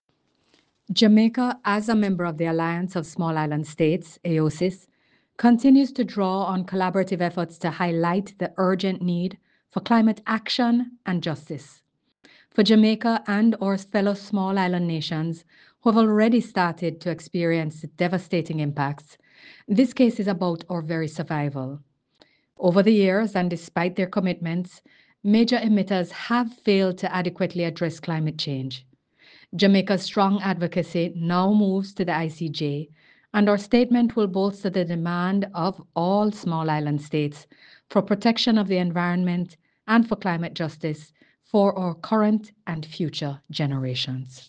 Minister-Johnson-Smith-speaks-on-ICJ-Hearings-for-Climate-Change.ogg